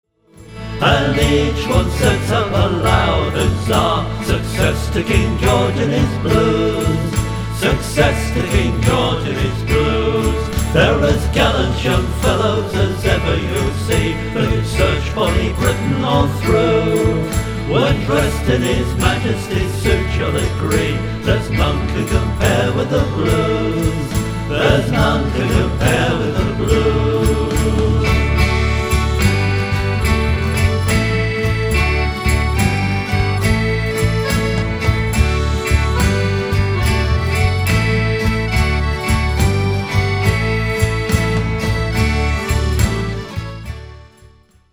A traditional song.
It tells of the muster of the Royal Horse Guards or ‘Blues and Royals’ as they were known. This song is still at the rehearsal stage.